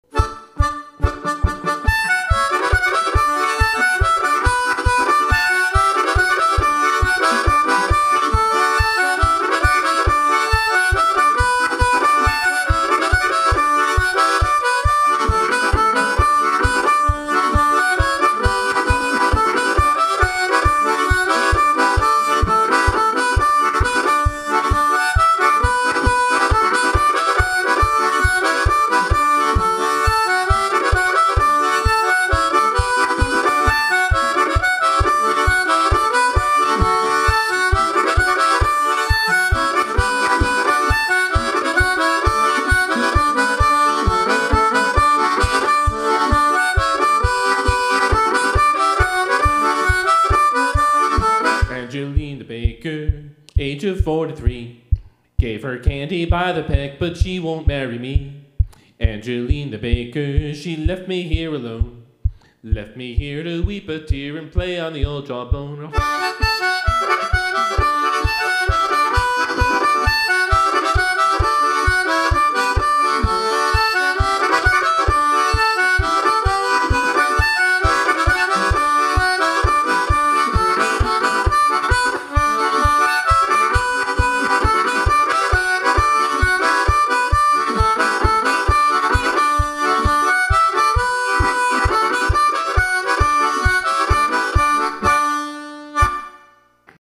Toronto Show — Reviews Are In
I have available on my web site recordings of the two traditional songs that I did during my solo set